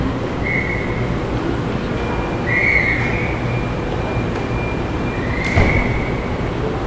Toys » Toy Train Whistle2
描述：One shot sample of a toy train whistle. Recorded with Zoom H4n onboard stereo mics.
标签： Toys Train Whistle Choochoo Zoom H4n Toy
声道立体声